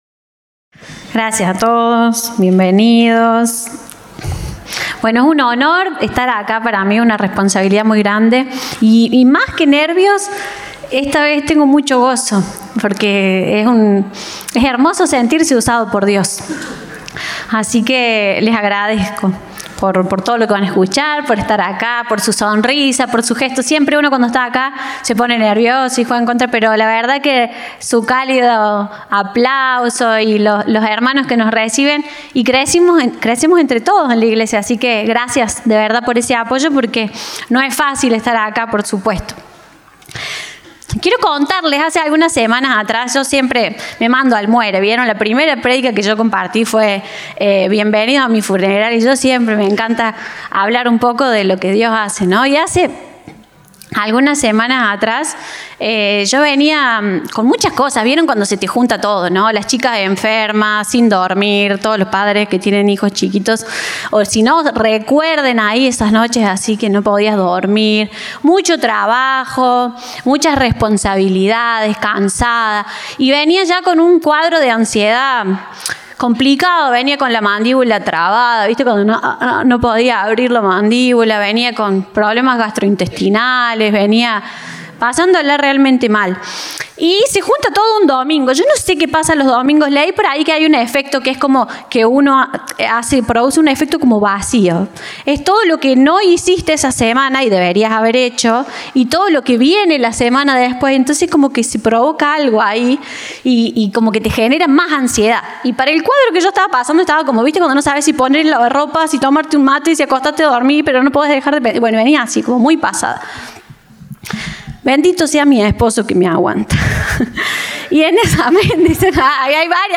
Compartimos el mensaje del Domingo 4 de Mayo de 2025 Orador invitado